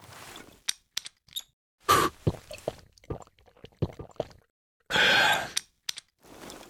vodka_drink.ogg